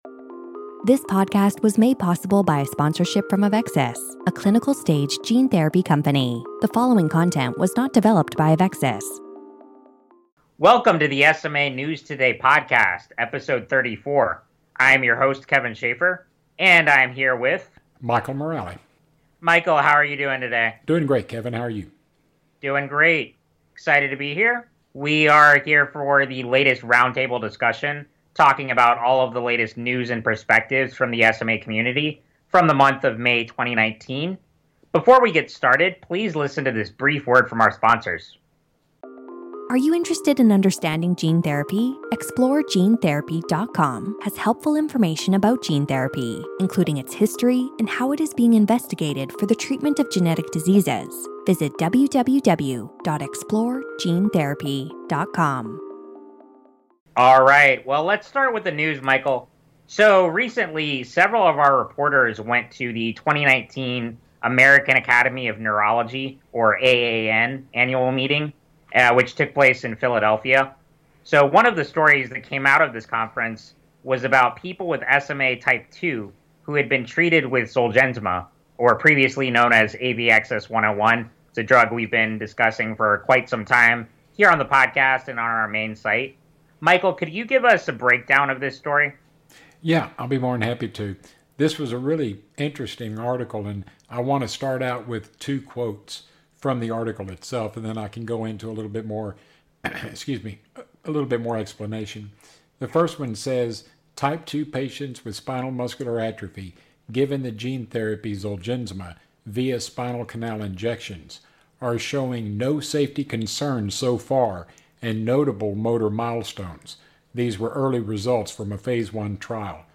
#34 - Roundtable Discussion - Discussion for May 2019